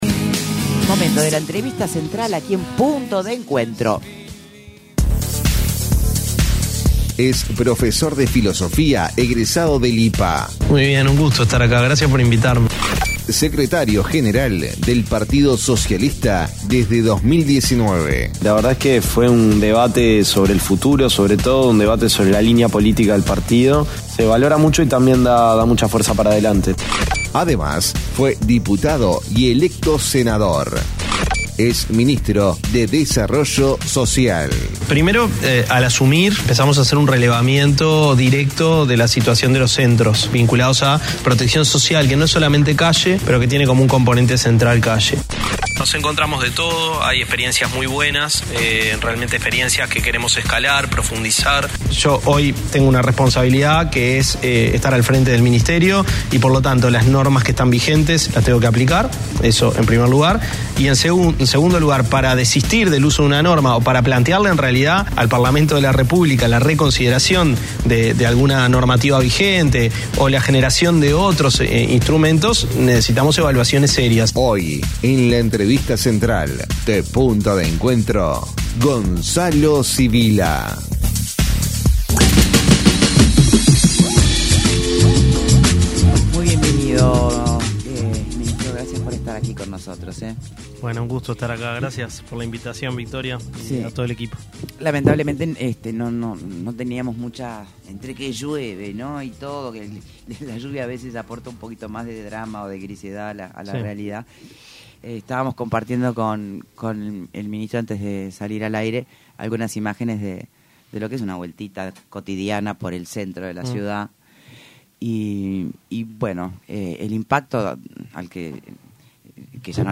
Escuche la entrevista completa aquí: El ministro de Desarrollo Social, Gonzalo Civilla dijo en entrevista con Punto de Encuentro que no se puede estigmatizar a las ollas populares como que se quedaban con comida que iba destinada a la gente.